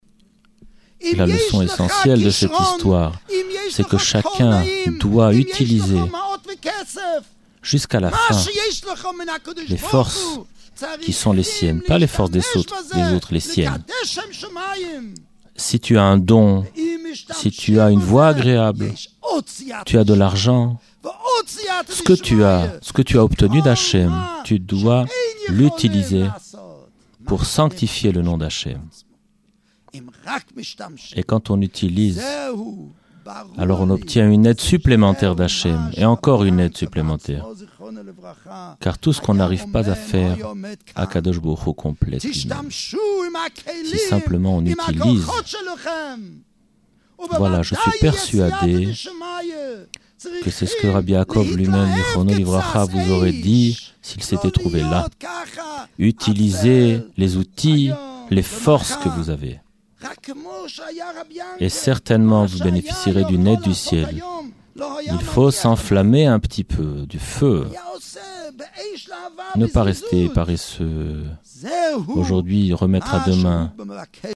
Traduction Simultnée